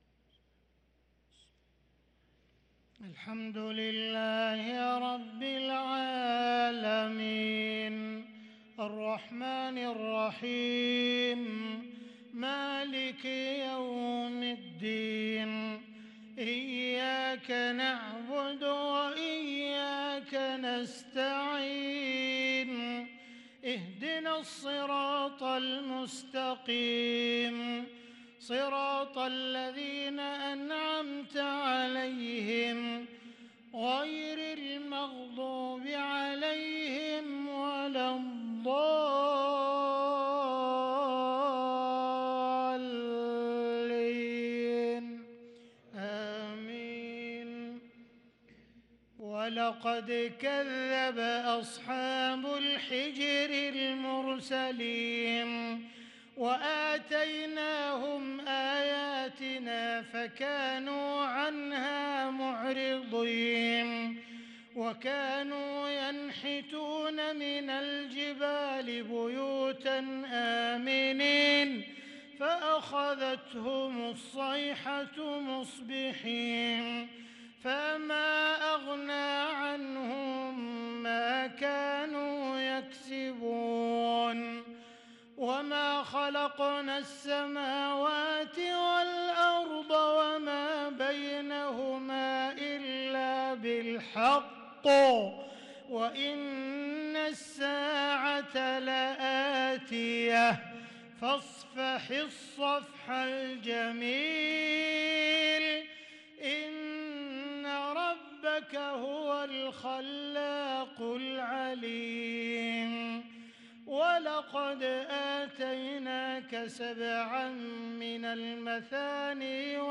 صلاة العشاء للقارئ عبدالرحمن السديس 6 ربيع الآخر 1444 هـ
تِلَاوَات الْحَرَمَيْن .